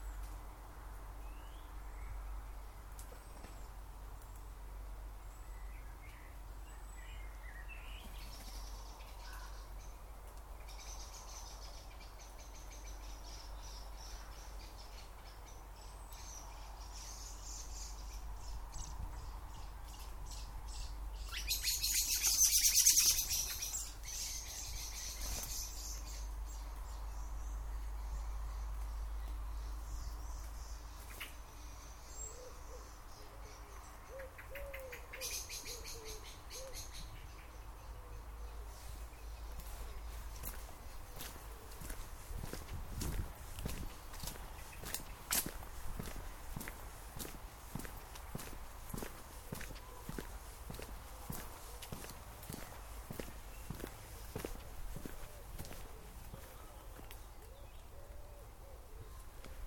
Birds and trees on path in the church yard